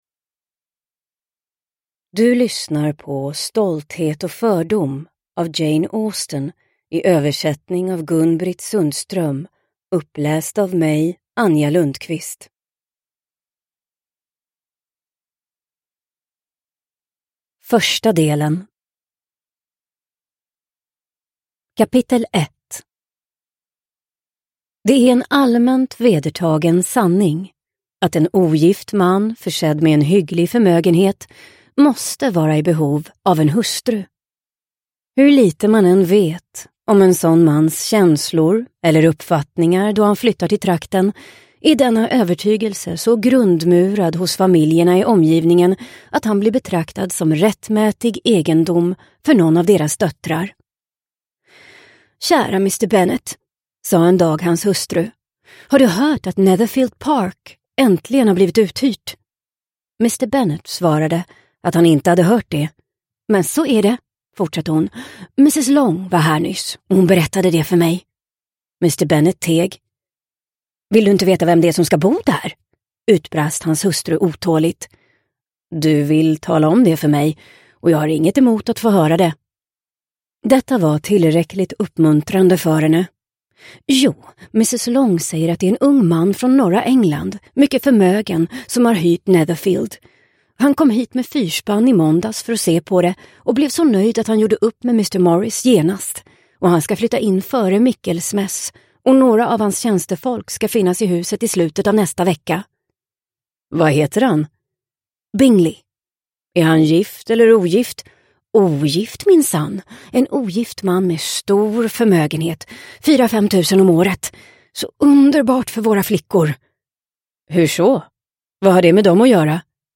Stolthet och fördom – Ljudbok – Laddas ner